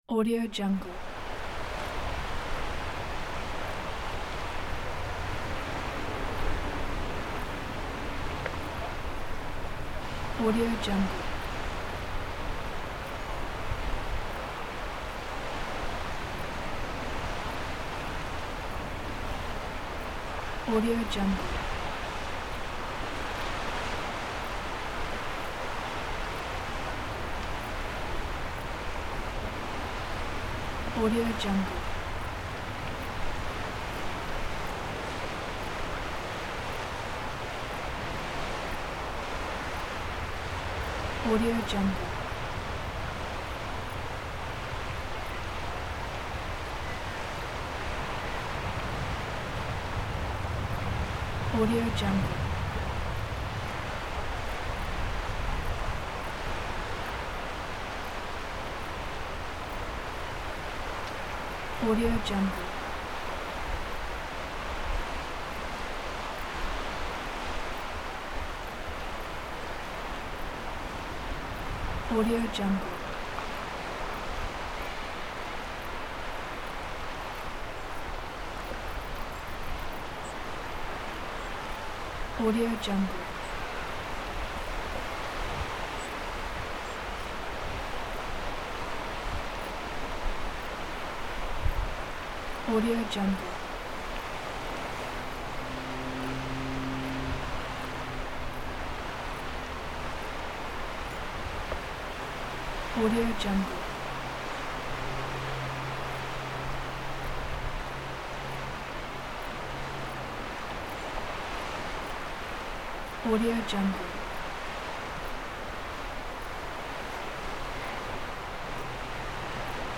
دانلود افکت صدای محیط ساحل دریا
این فایل با کیفیت استودیویی، صدای واقعی امواج دریا، نسیم ساحل و پرندگان دریایی را به طور طبیعی شبیه‌سازی کرده است.
• کیفیت استودیویی: صدای ضبط شده در محیط طبیعی و با استفاده از تجهیزات حرفه‌ای، کیفیت بسیار بالایی دارد و به پروژه‌های شما حرفه‌ای‌تر می‌بخشد.
از صدای امواج آرام و لایه‌لایه گرفته تا صدای پرندگان دریایی و نسیم ساحل، همه چیز در این فایل موجود است.
16-Bit Stereo, 44.1 kHz